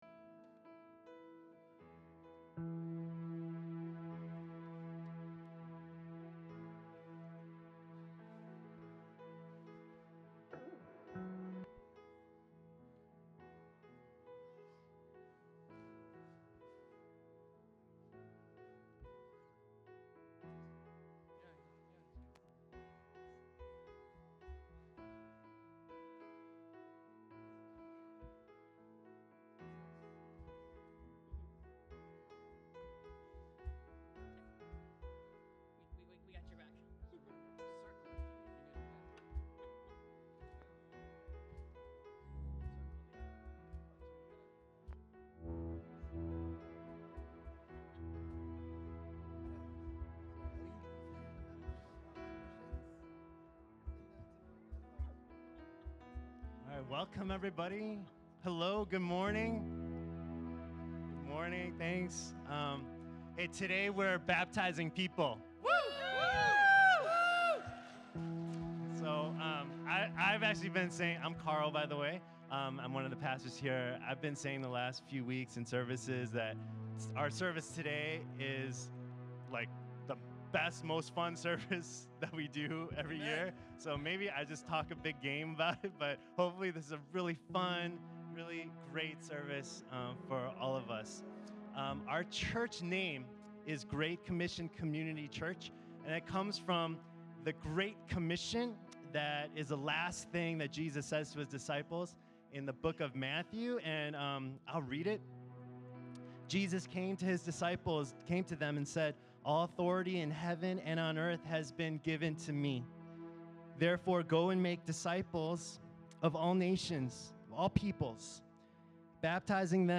Baptism Service